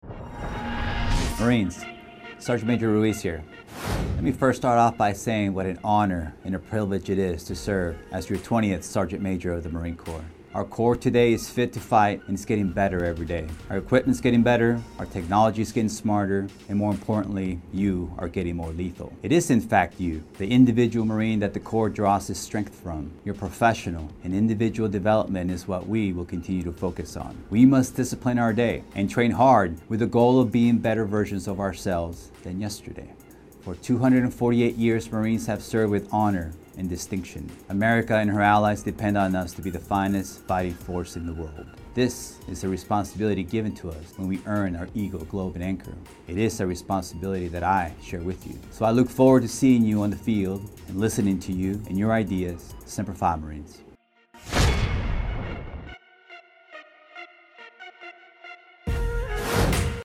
Marine Minute: Message From Sgt. Maj. Ruiz